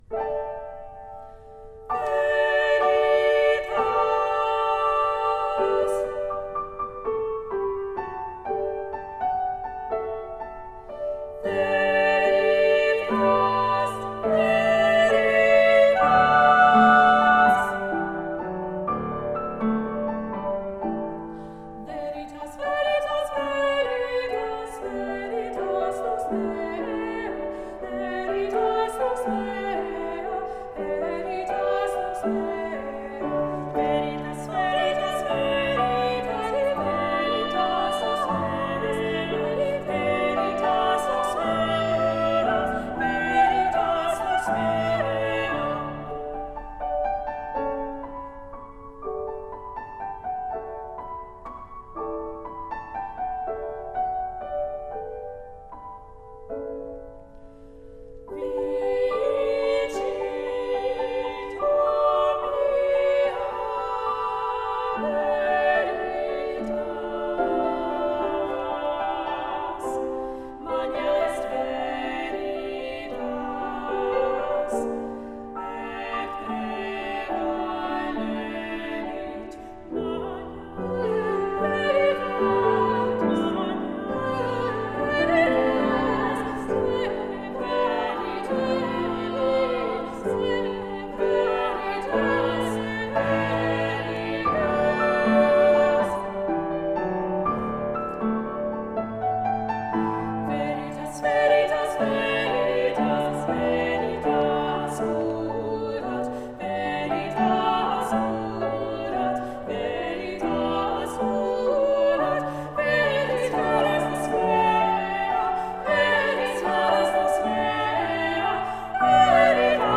Voicing: SSA
Instrumentation: piano
choral series